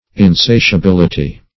Search Result for " insatiability" : The Collaborative International Dictionary of English v.0.48: Insatiability \In*sa`tia*bil"i*ty\, n., [L. insatiabilitas; cf. F. insatiabilite.] The state or quality of being insatiable; insatiableness.